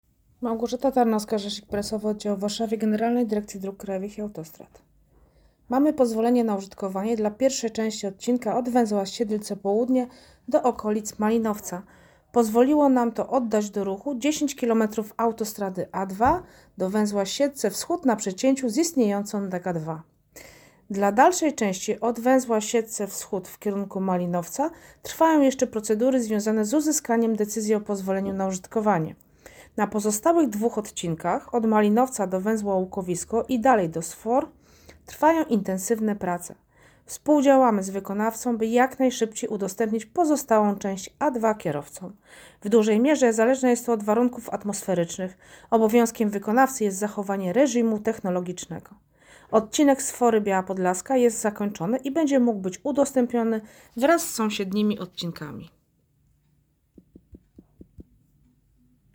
wypowiedź rzecznika - A2 - PnU Siedlce Płd.-Siedlce Wsch.